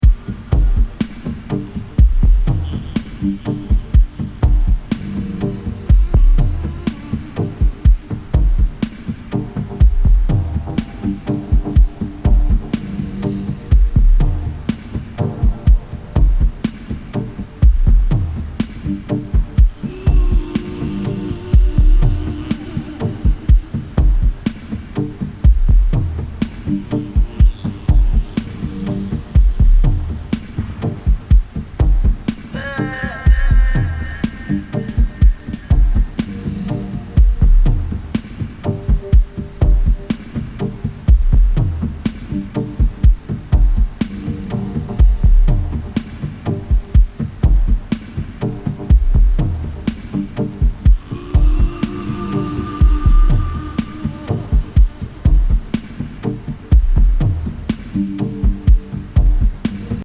今の次世代ダビーテクノ・サウンドの原型ともいえるミニマルで激ディープ・グルーヴ。
TECHNO & HOUSE